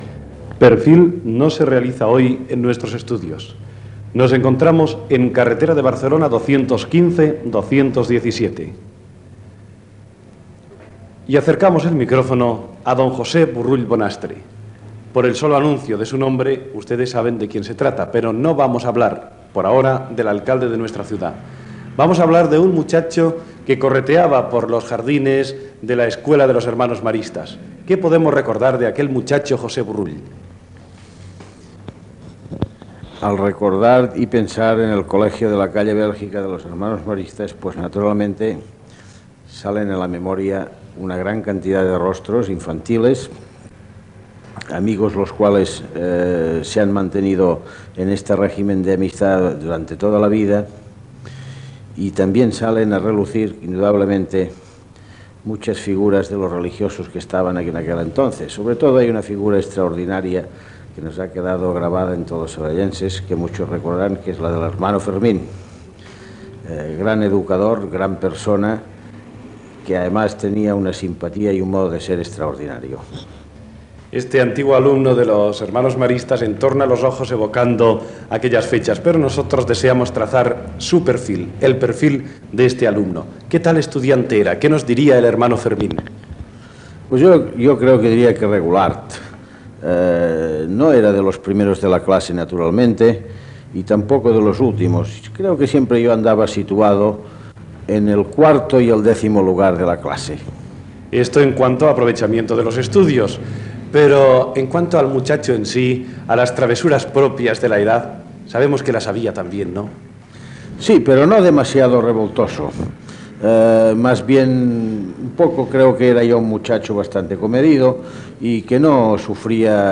cbc4d7225dbd849bc9c55d987ec999c480cab0ed.mp3 Títol Ràdio Sabadell EAJ-20 - Perfil Emissora Ràdio Sabadell EAJ-20 Titularitat Privada local Nom programa Perfil Descripció Entrevista a l'alcalde de Sabadell Josep Borrull Bonastre. S'hi parla dels records d'infància, l'esport, aficions, com va ser la seva possessió com alcalde de la ciutat